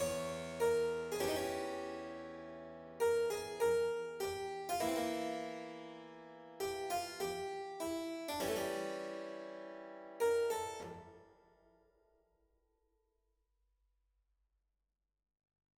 J. S. 바흐의 영국 모음곡 3번 사라반드, 마디 17–19